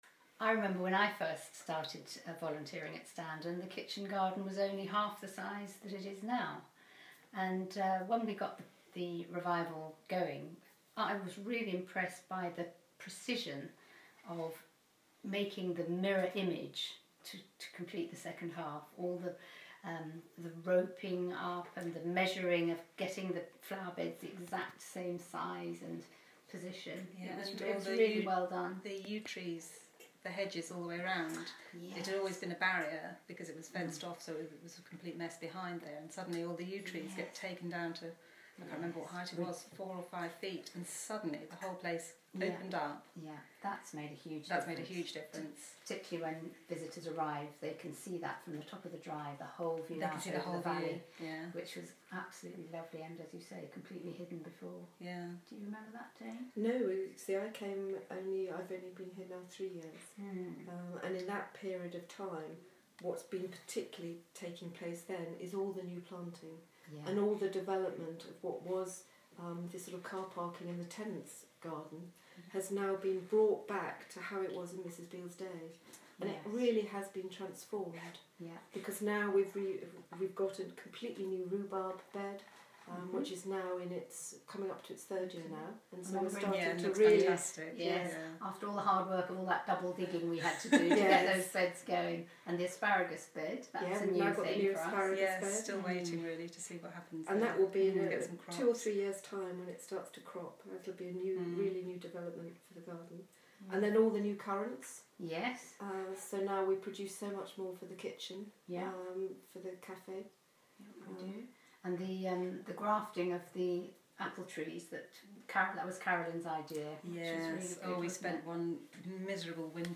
Hear from Garden Revival volunteers at Standen, a National Trust place in West Sussex.